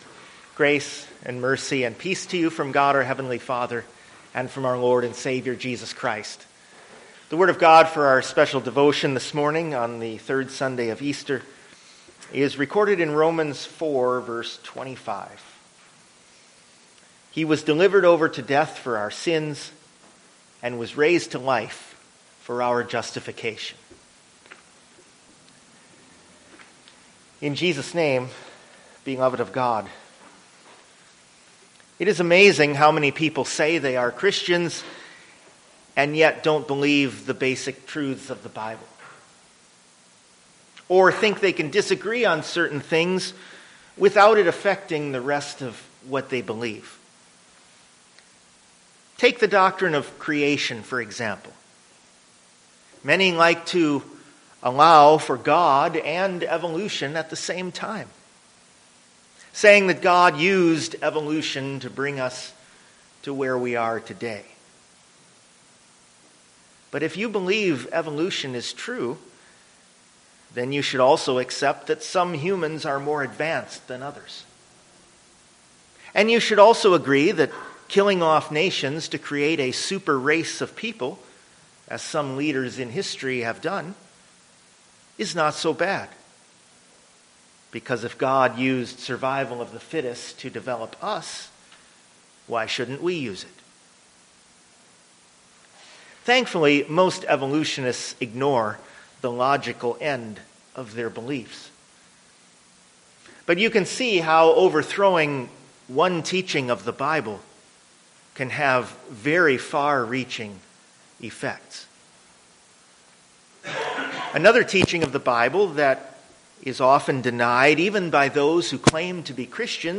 Service Type: Easter